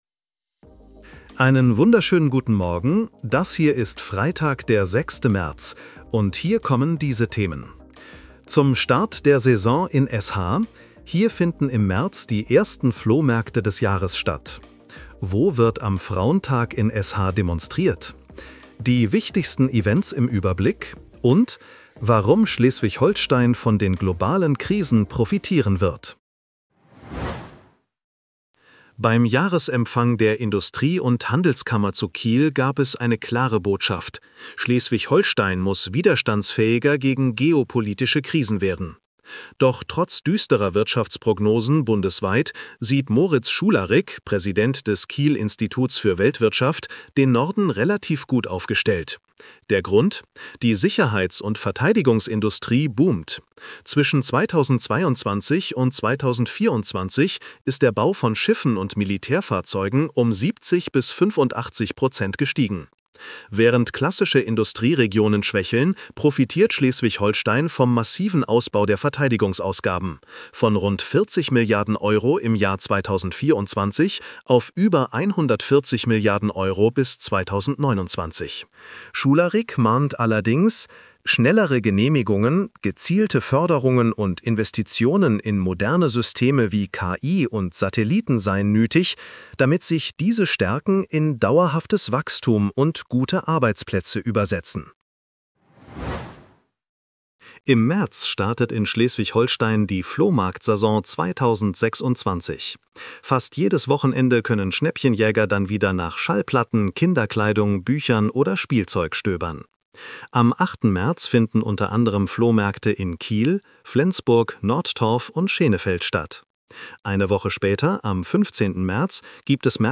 Nachrichten-Botcast bekommst Du ab 7:30 Uhr die wichtigsten Infos